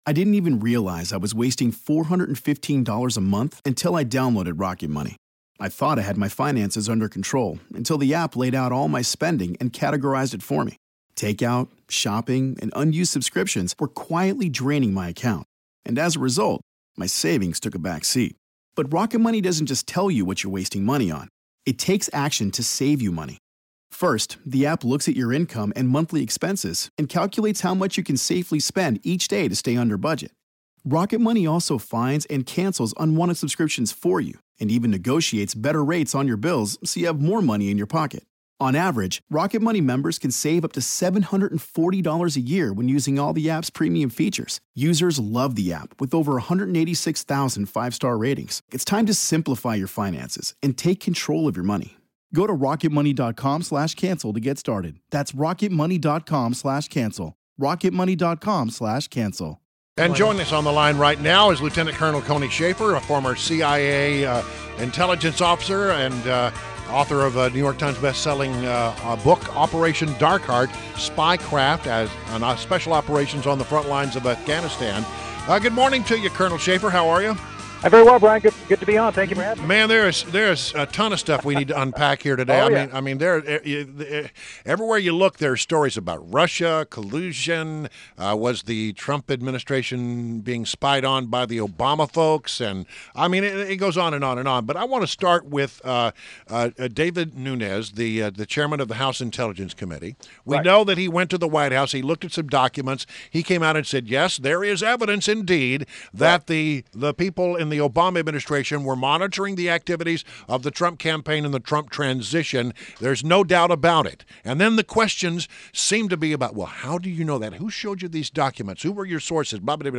WMAL Interview - TONY SHAFFER - 03.31.17